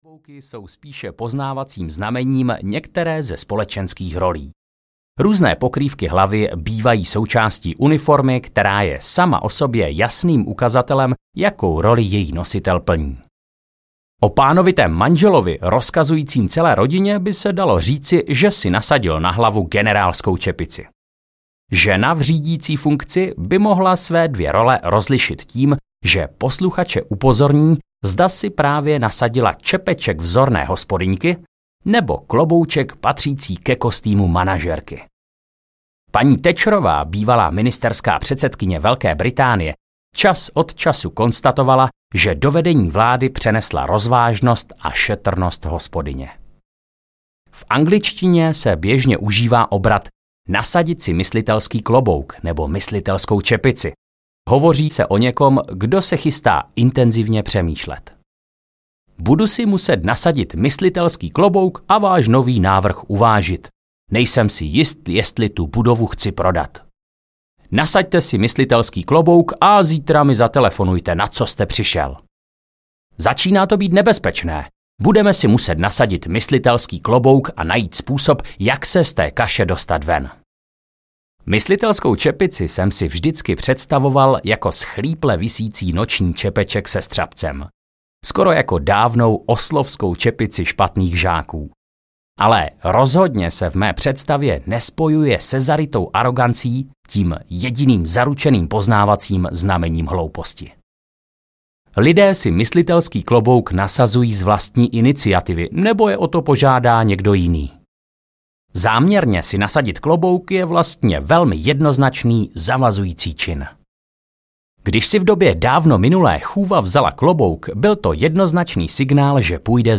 Šest klobouků aneb Jak myslet audiokniha
Ukázka z knihy
sest-klobouku-aneb-jak-myslet-audiokniha